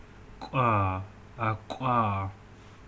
labzd velar ejective
Labialized_velar_ejective.ogg.mp3